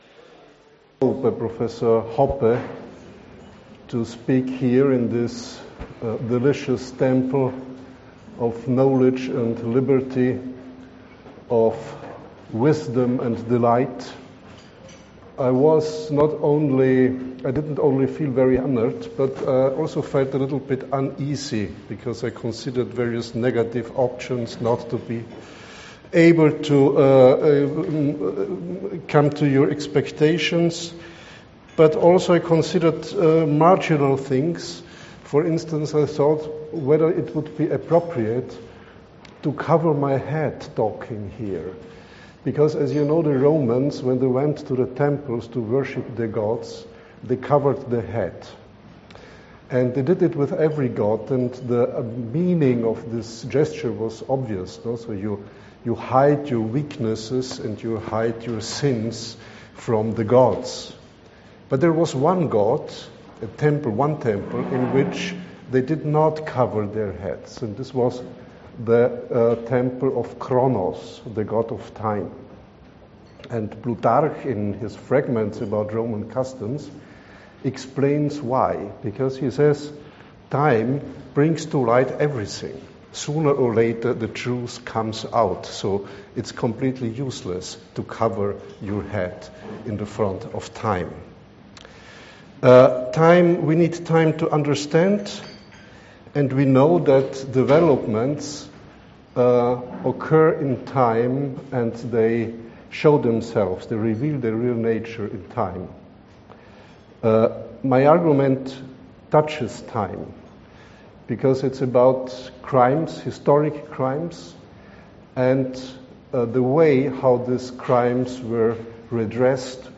This lecture is from the 2012 meeting of the Pr…